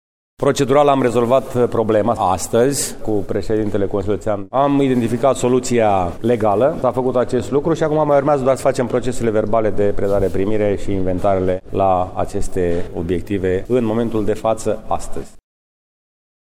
Primarul George Scripcaru.